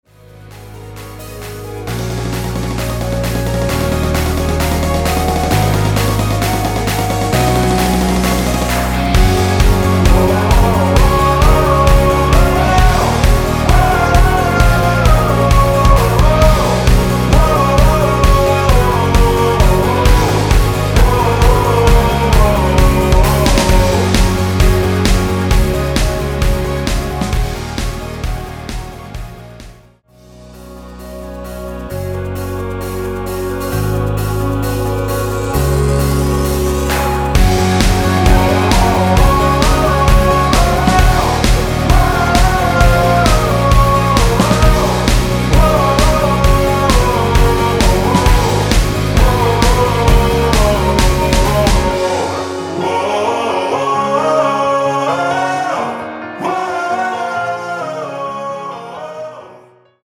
원키에서(-3)내린 멜로디와 코러스 포함된 MR입니다.(미리듣기 확인)
Gb
앞부분30초, 뒷부분30초씩 편집해서 올려 드리고 있습니다.
중간에 음이 끈어지고 다시 나오는 이유는